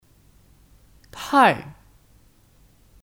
太 Tài (Kata keterangan): Terlalu